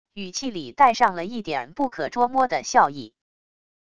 语气里带上了一点不可捉摸的笑意wav音频生成系统WAV Audio Player